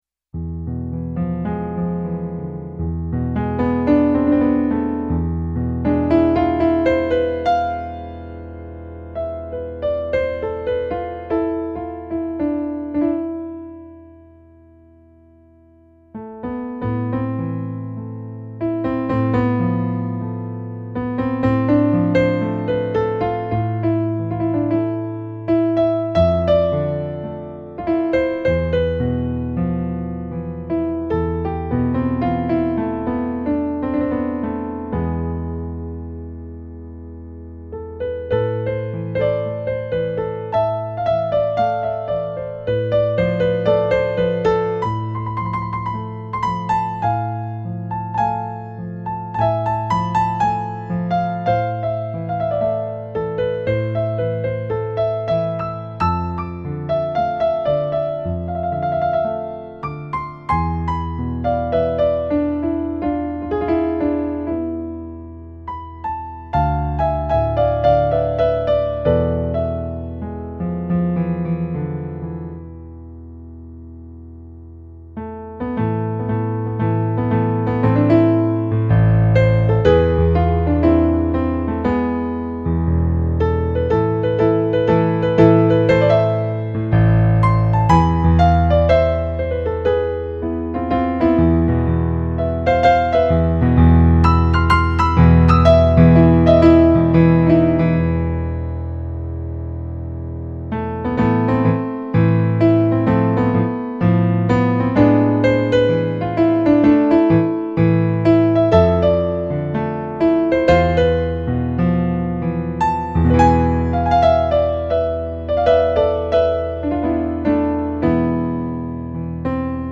eight piano solo arrangements.  34 pages.
snake charmer remix